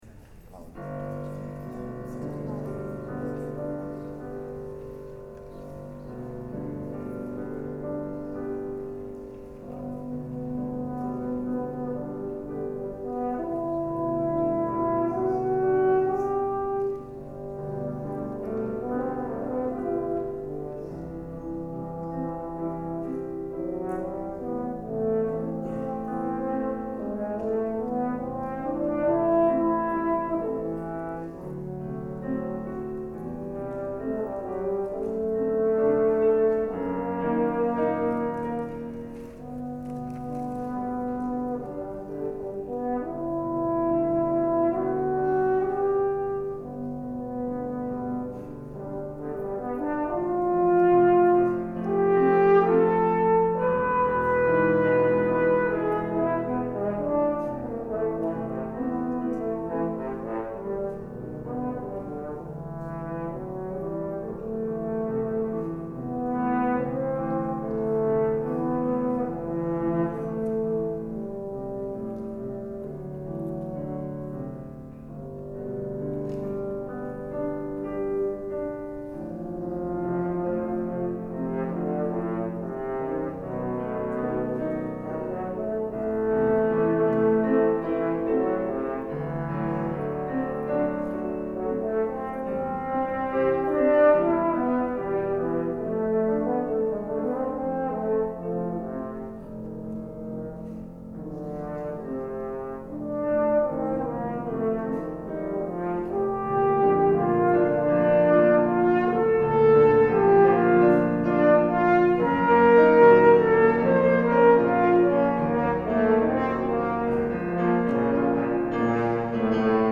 This summer I arranged some music for horn and piano, including  Six Studies in English Folk-Song by Ralph Vaughan Williams, and the “Meditation” from Thaïs, by Jules MassenetThere are some beautiful melodies in these pieces, and I thought they might transfer well to the horn.
The original scoring is for solo violin and orchestra, but I worked from a violin and piano version obtained on IMSLP.
This puts the piano part in a pretty low range, but it seemed to balance out ok with the horn.  There are some octave transpositions in a few places, and many passages really work out the low register.
The pedal D at the end is optional!
Instead of the bright D major, we would get a dark Eb major.